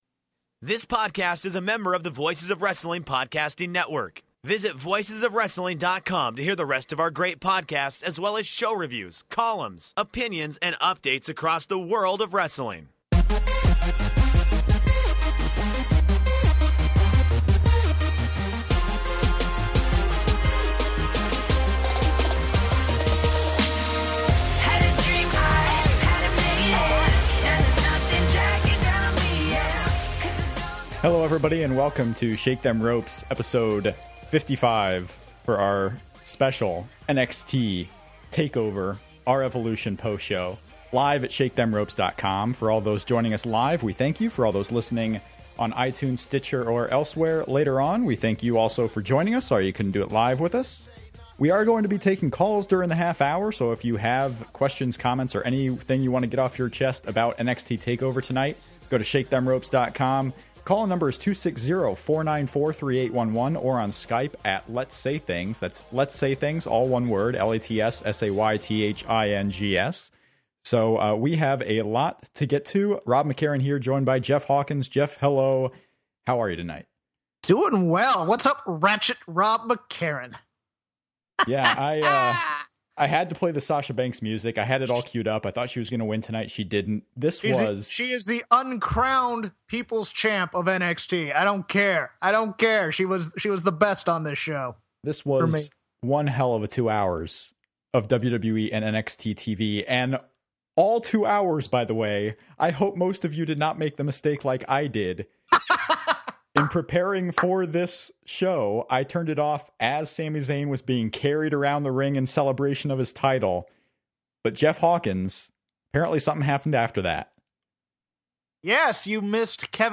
STR 55: NXT Takeover [R]Evolution Live Reaction
We run down possibly the best WWE event of the entire year, including the winners, losers, and debuts. From Kevin Owens to Sami Zayn, everything gets covered in this episode. We take calls and tweets as well as others join in on the fun!